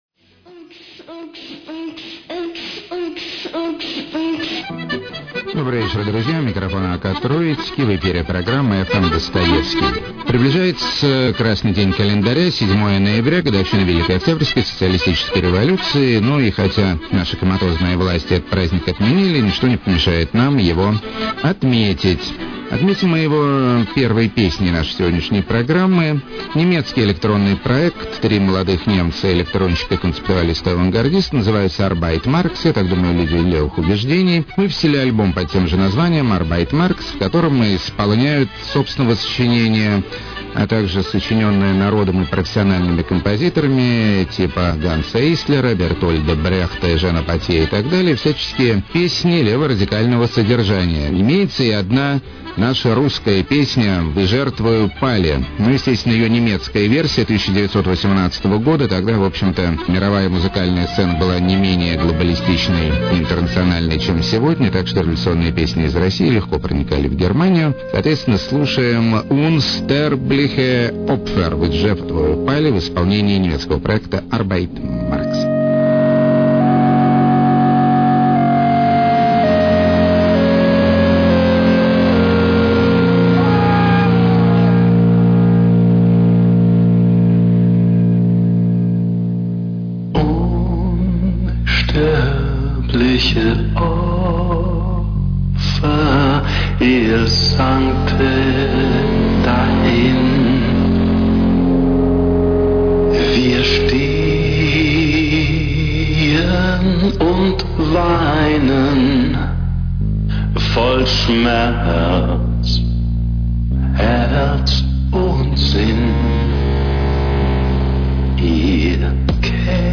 Записано с большими помехами.